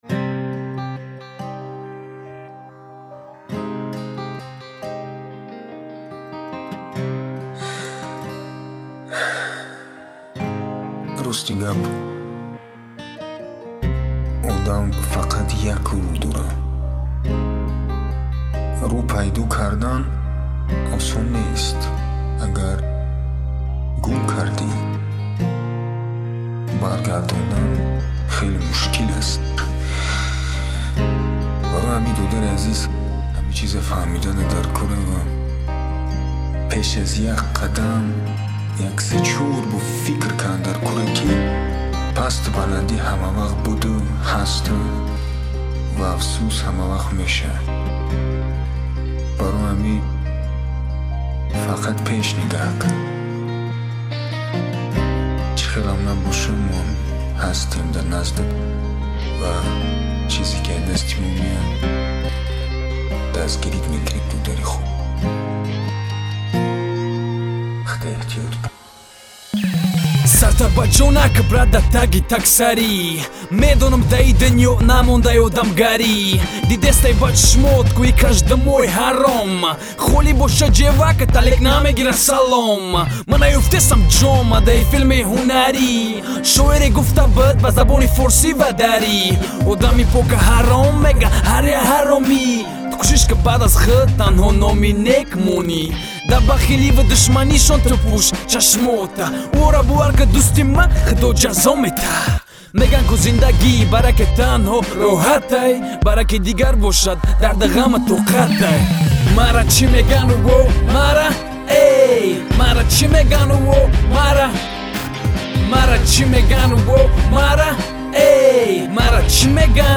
Главная » Файлы » Каталог Таджикских МР3 » Тадж. Rap